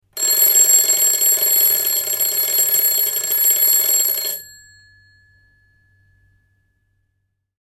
دانلود آهنگ زنگ 4 از افکت صوتی اشیاء
دانلود صدای زنگ 4 از ساعد نیوز با لینک مستقیم و کیفیت بالا
جلوه های صوتی
برچسب: دانلود آهنگ های افکت صوتی اشیاء دانلود آلبوم صدای زنگ هشدار از افکت صوتی اشیاء